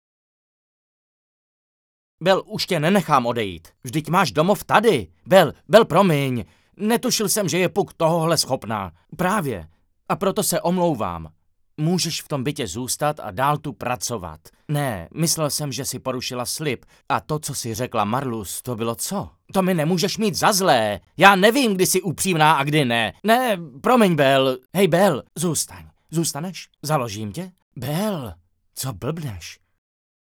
ukázka Dabingu: